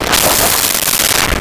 Whip Thick Whoosh
Whip Thick Whoosh.wav